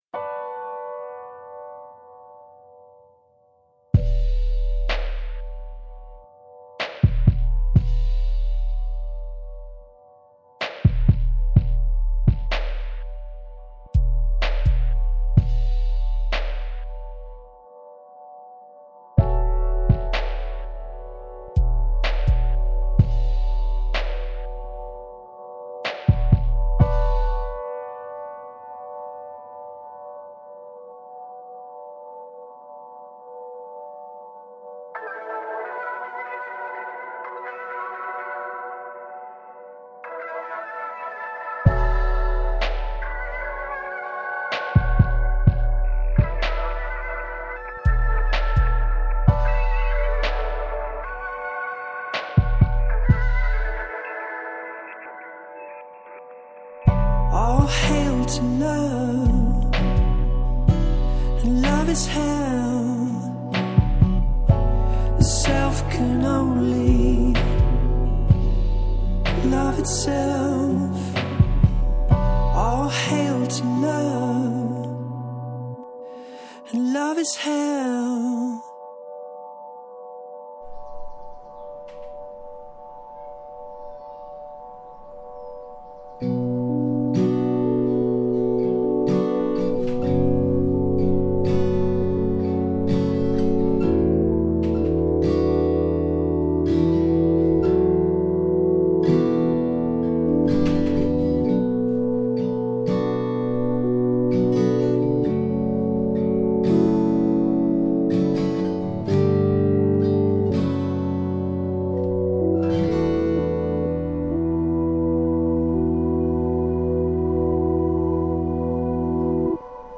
Progressive Rock, Electronic, Pop